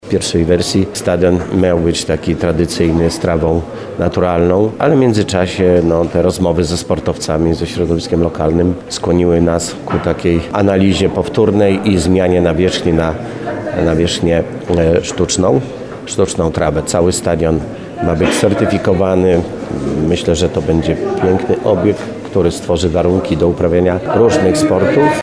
Rozpoczęcie robót było poprzedzone dużymi staraniami o fundusze rozpoczęły się w 2023 roku – mówi wójt Leszek Skowron.